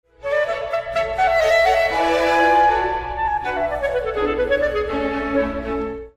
A hundred thirty years earlier, Mozart had reveled in the fresh, airy lightness of the major tonality which superseded stolid Renaissance modal forms around 1600.
mozart-clarinet-3.mp3